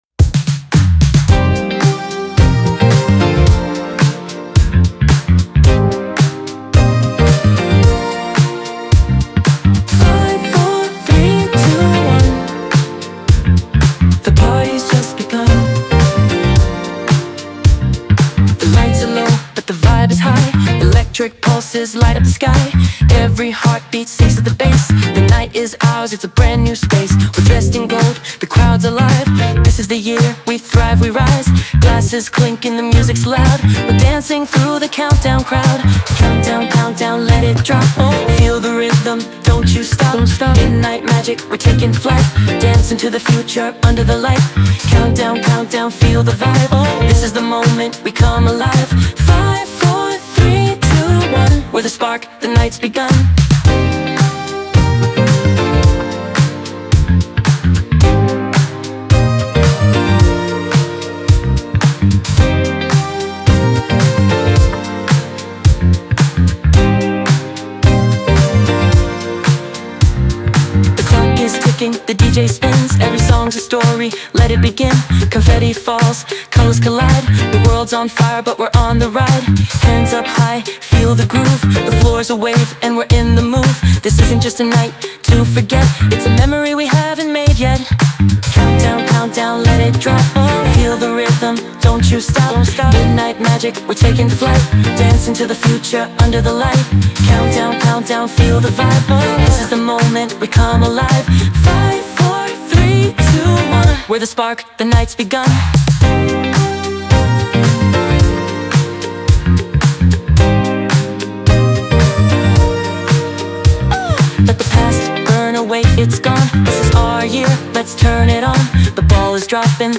который сочетает элементы фанк, соул и электронную музыку